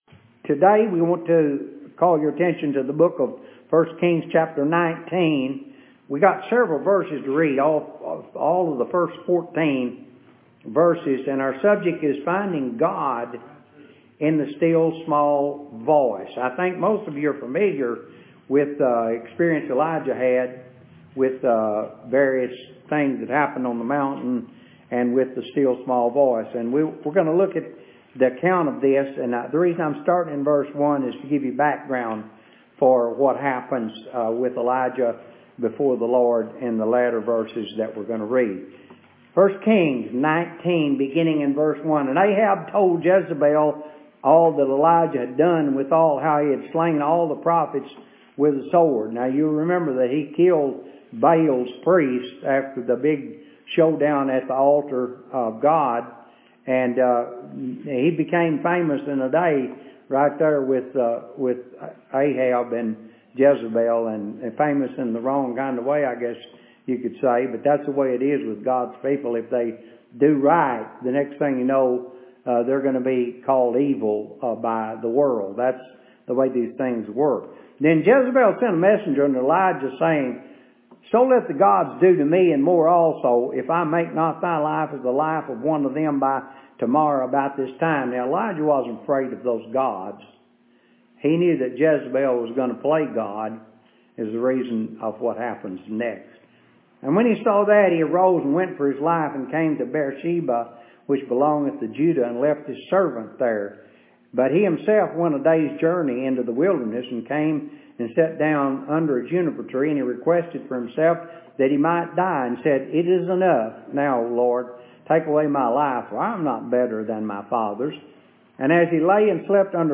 Clicking on an item will open an audio sermon on the subject.